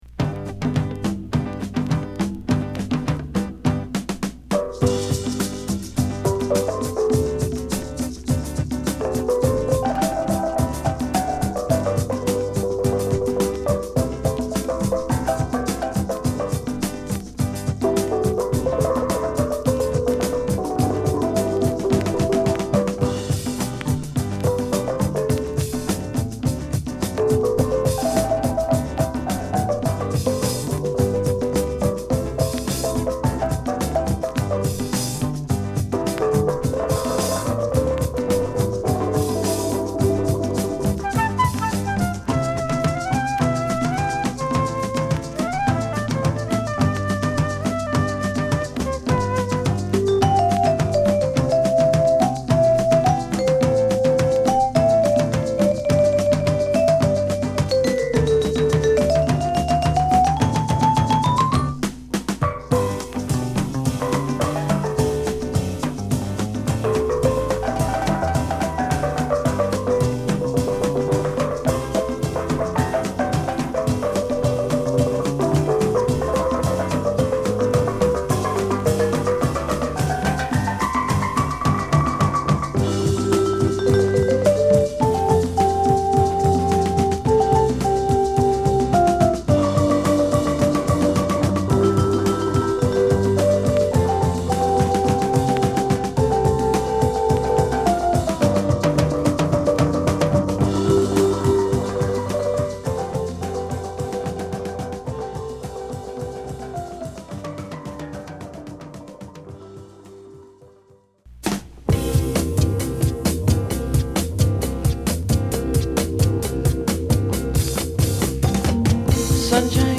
スチールパンを取り入れたラテン・ロック・バンド